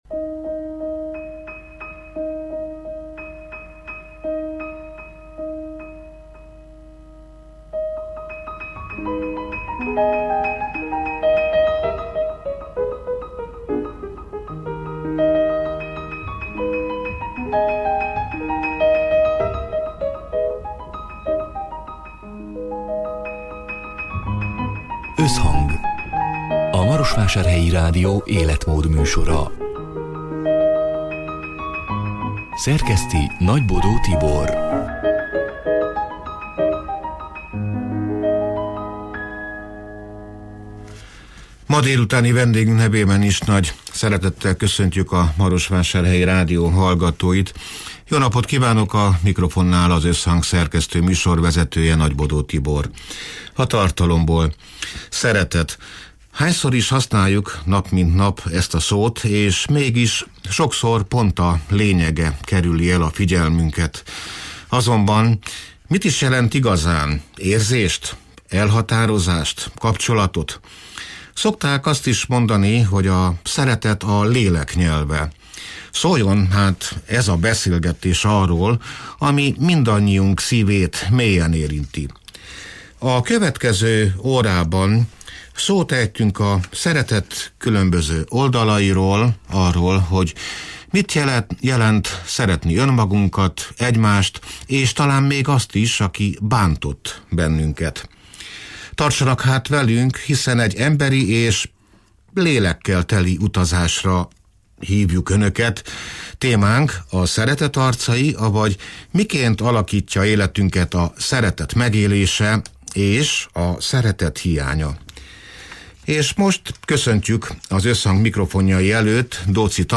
(elhangzott: 2025. július 9-én, szerdán délután hat órától élőben)
Szóljon hát ez a beszélgetés arról, ami mindannyiunk szívét mélyen érinti.